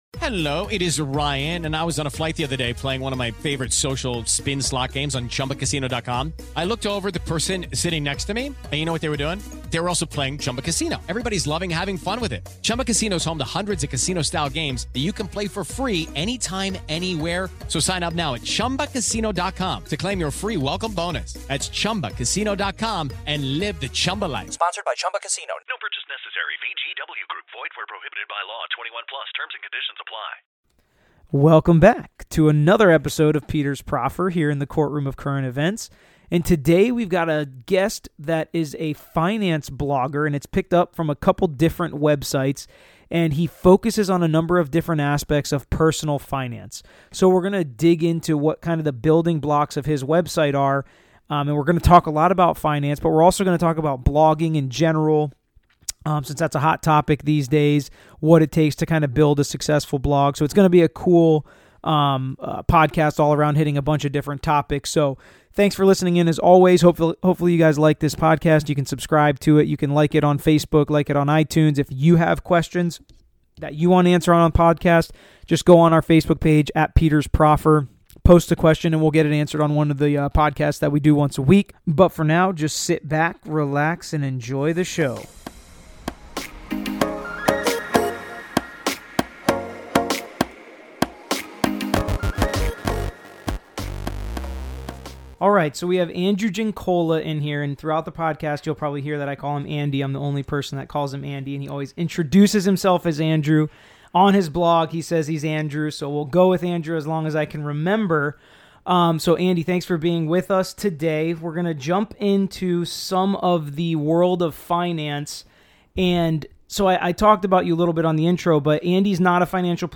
we interview special guest